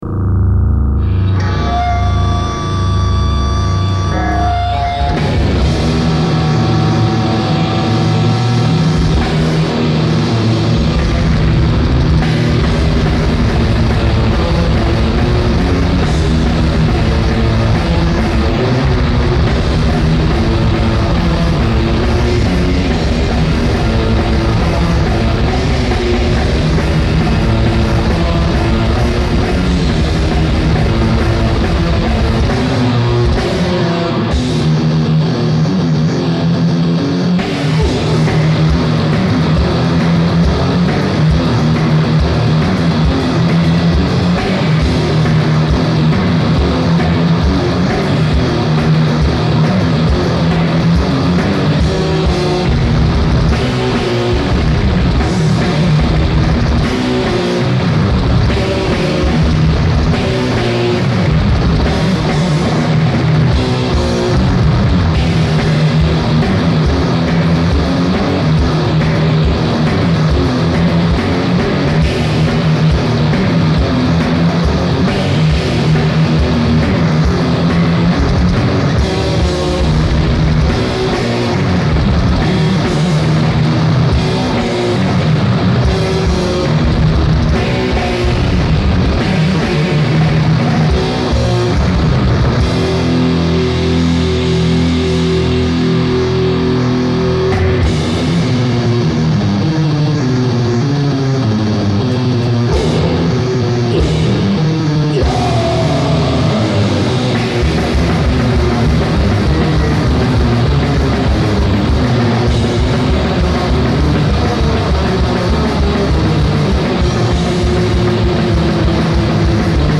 Genre: Metal
Revel in real underground Swedish death metal!